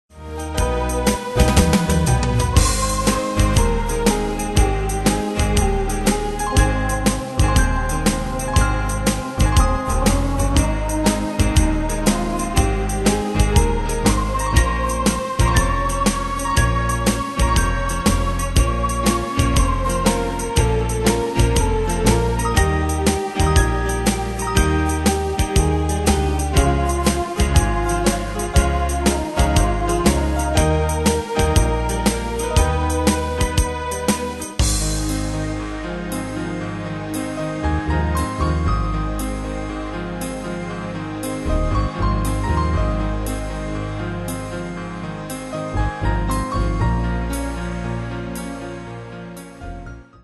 Style: PopFranco Ane/Year: 1975 Tempo: 120 Durée/Time: 3.43
Danse/Dance: TwoSteps Cat Id.
Pro Backing Tracks